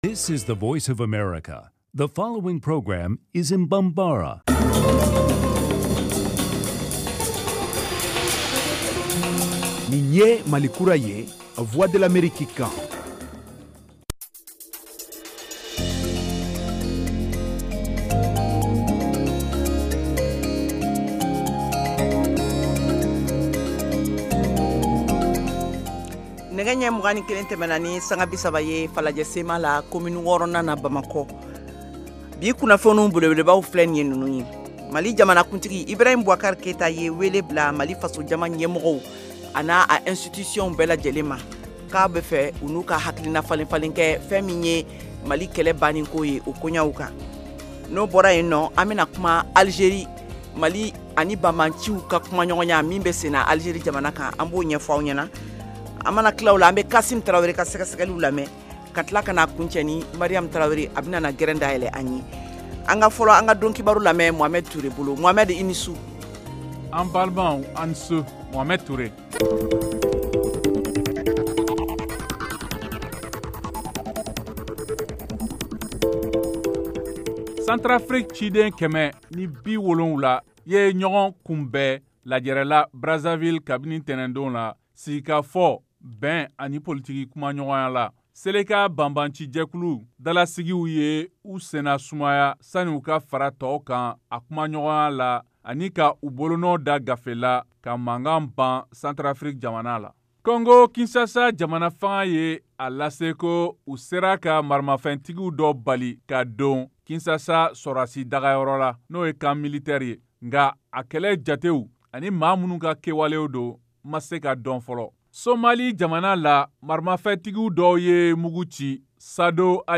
Emission quotidienne en langue bambara